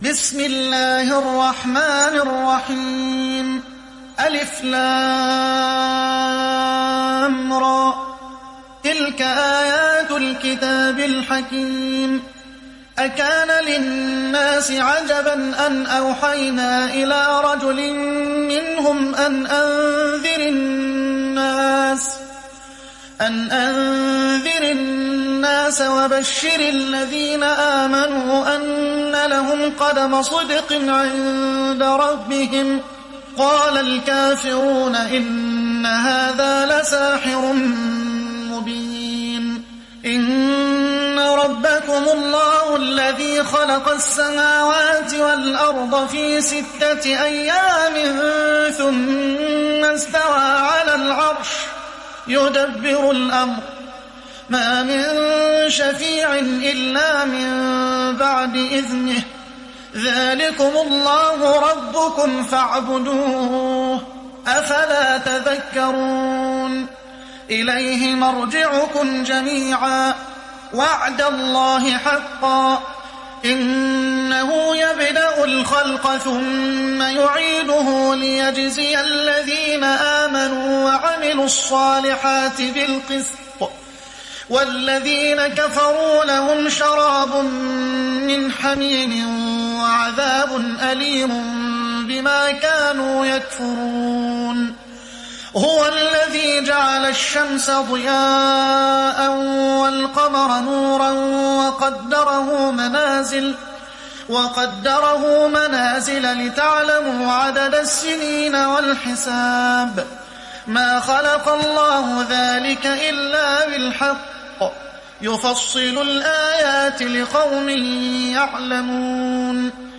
(Riwayat Hafs)